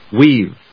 /wiːv(米国英語), wi:v(英国英語)/